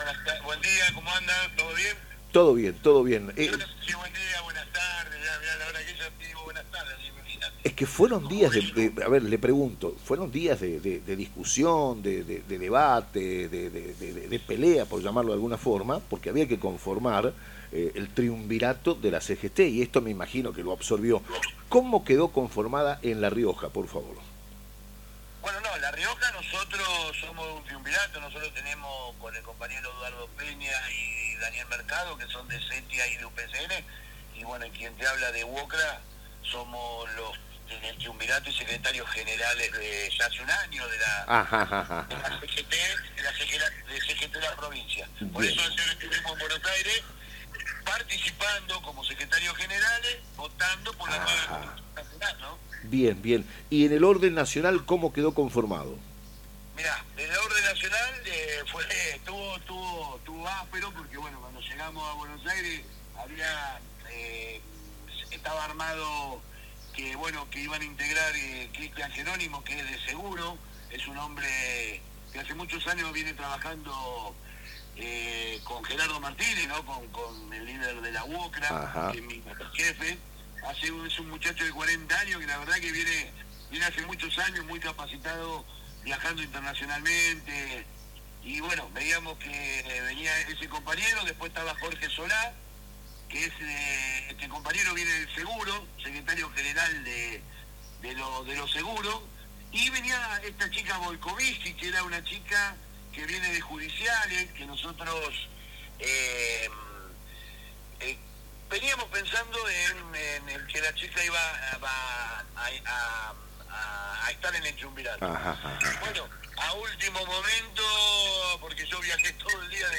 En diálogo con Radio Libertad, en el Programa Nueva Época